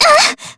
Jane_L-Vox_Damage_jp_01.wav